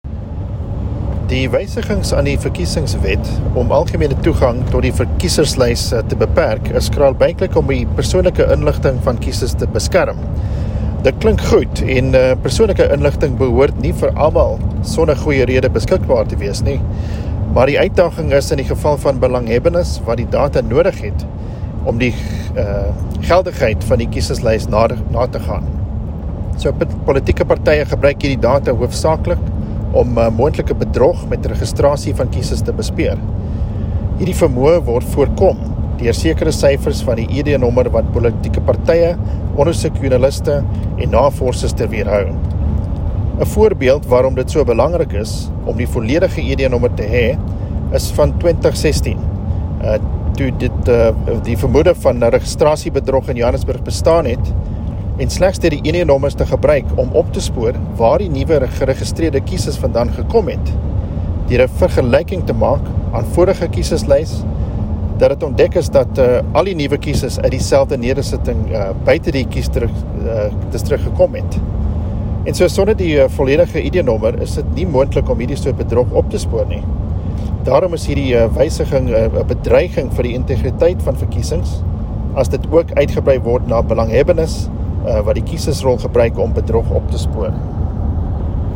Issued by Adrian Roos MP – DA Shadow Deputy Minister of Home Affairs
Please find attached a soundbite in